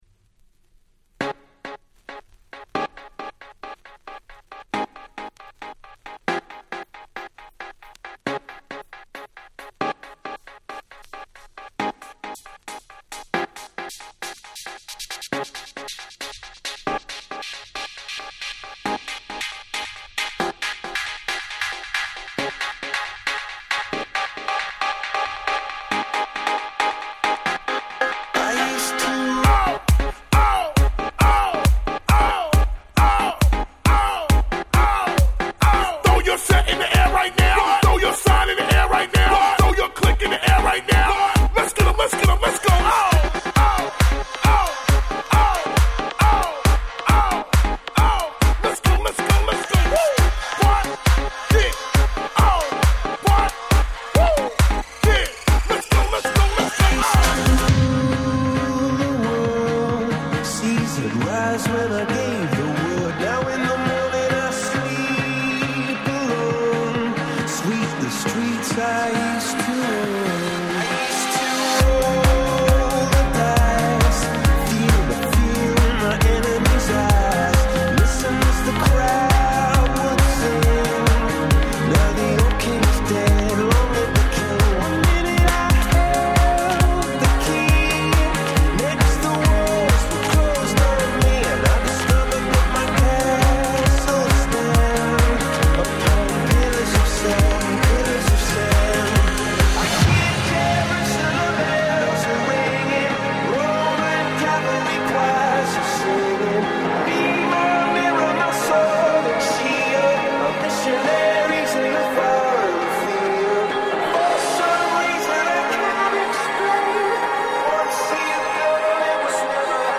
壮大な原曲の良さを生かしつつもしっかりClub映えするEDMにRemix !!